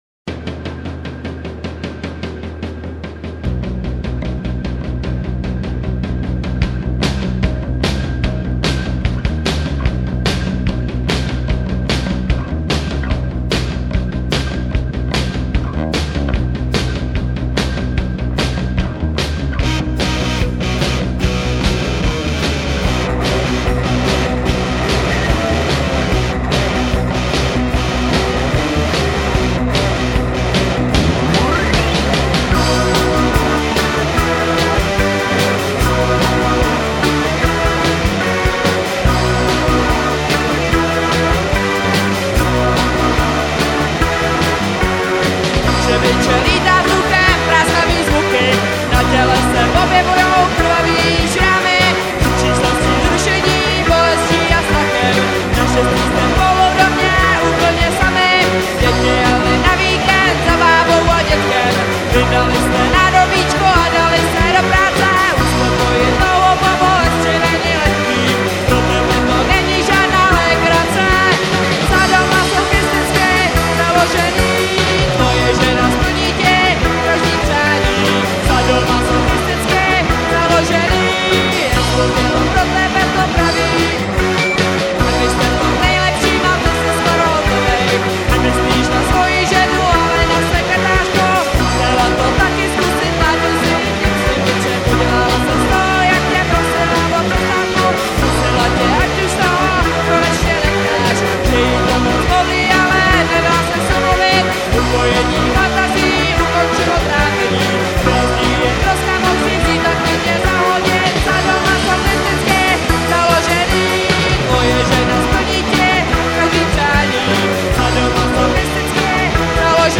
Natočeno živě v dubnu 1999 v Divadle Pod Lampou.